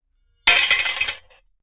Metal_71.wav